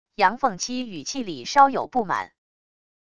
杨凤栖语气里稍有不满wav音频